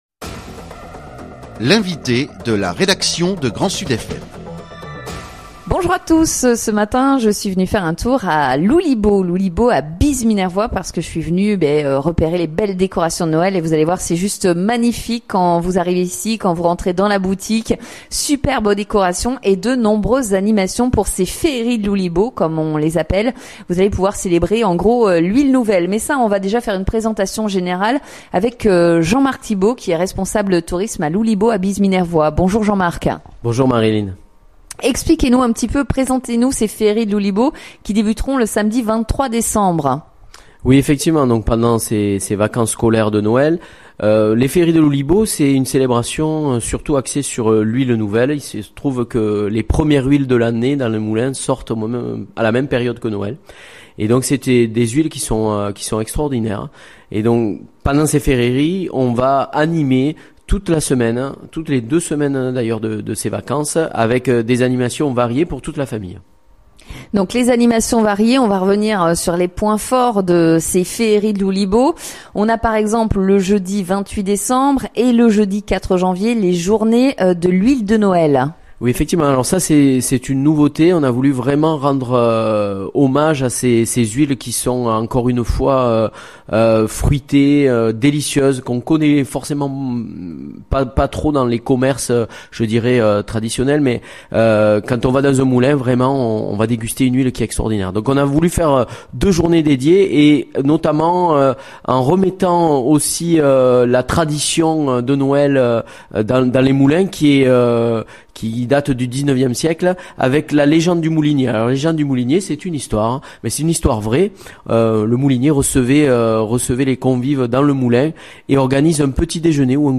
21-11-24 : Yves BASTIE, maire de Sallèles d’Aude